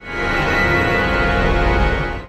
The circle of fourths as a chord, distributed across the orchestra.